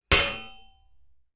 Metal_57.wav